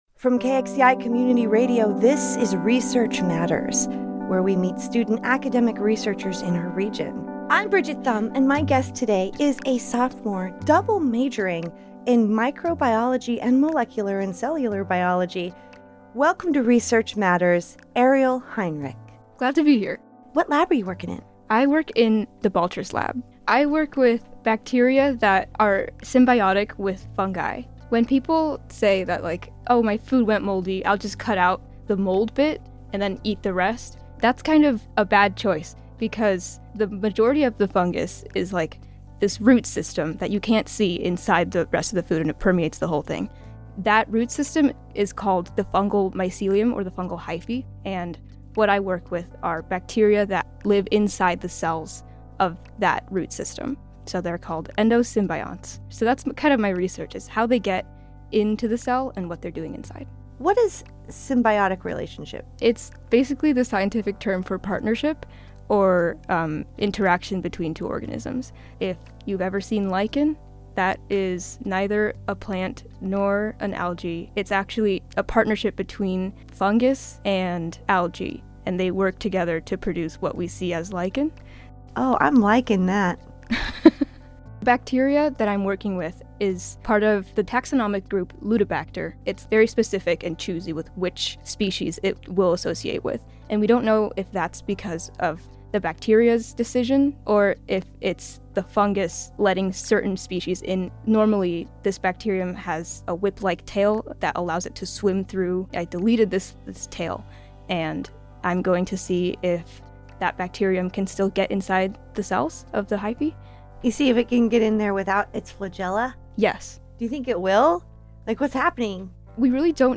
Research Matters is Hosted, Recorded, Engineered, and Produced at KXCI Tucson